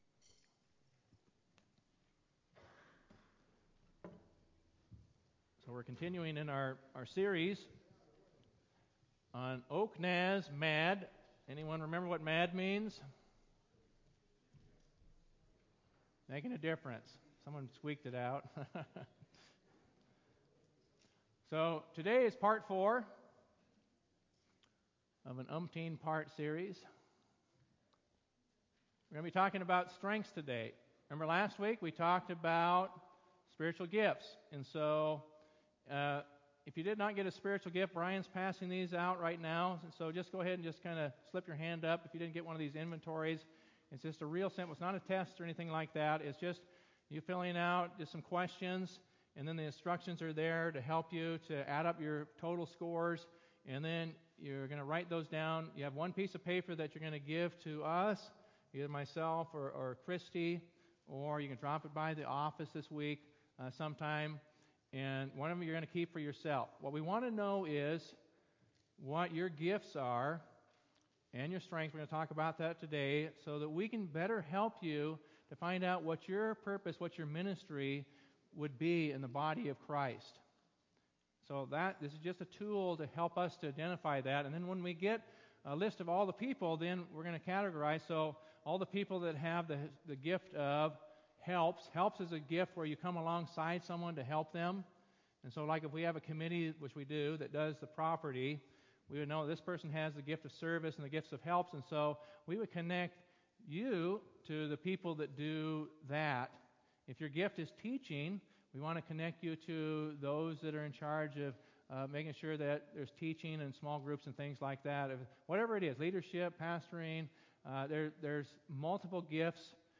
Sermon-1-28-18-CD.mp3